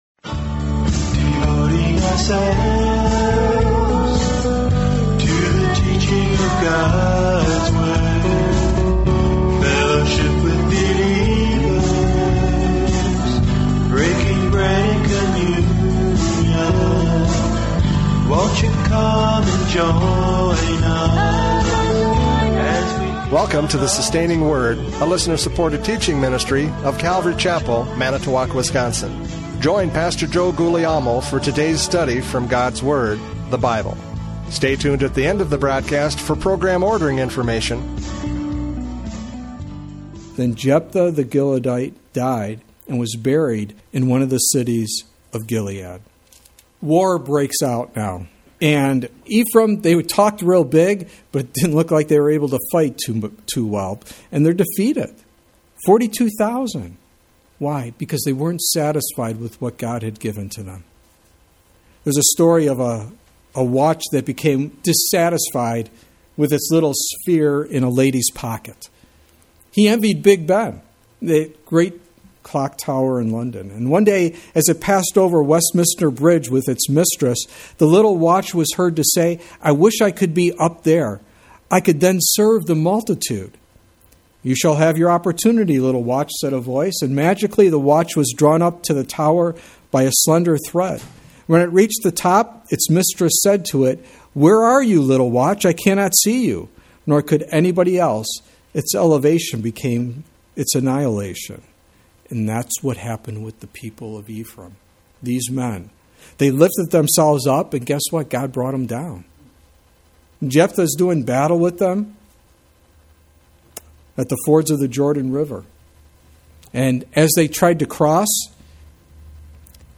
Judges 12 Service Type: Radio Programs « Judges 12 Turmoil Against the Brethren!